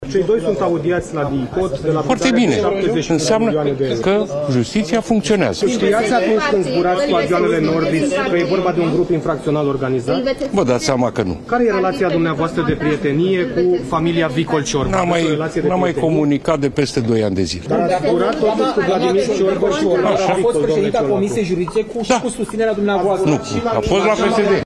„Înseamnă că justiția funcționează” – a spus premierul Marcel Ciolacu, întrebat de jurnaliști despre perchezițiile și ancheta DIICOT vizând scandalul Nordis.